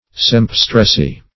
Sempstressy \Semp"stress*y\, n.